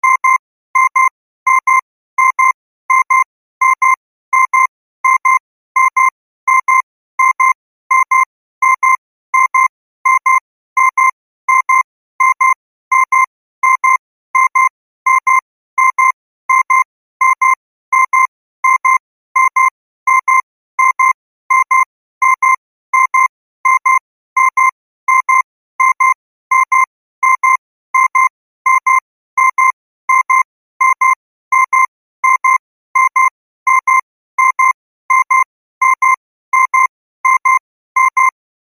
デジタル目覚まし時計をモチーフにした、ループ可能な楽曲です。
音符が2つ上がっていることから、少し高めの音域で構成されています。繰り返されるリズムとメロディー。